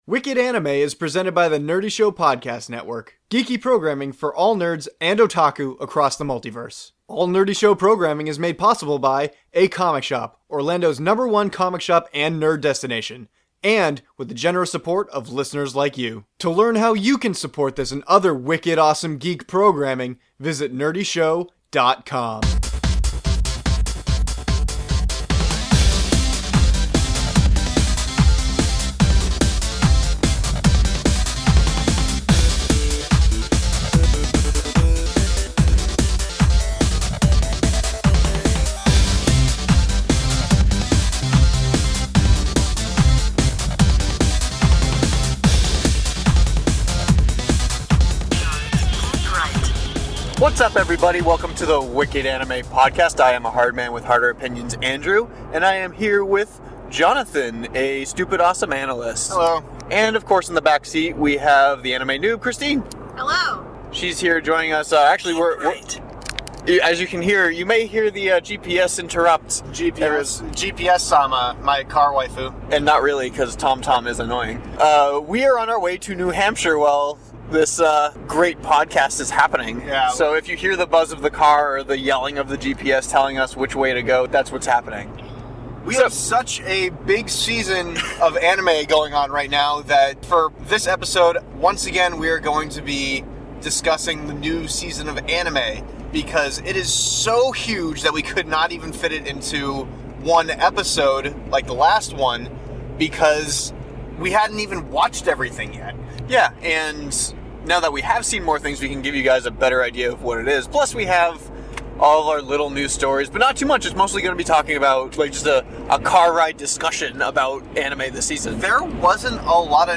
There is so much to watch, we are still covering it all. We recorded this episode on our way up to scenic New Hampshire and we take the opportunity to nerd out on some new anime!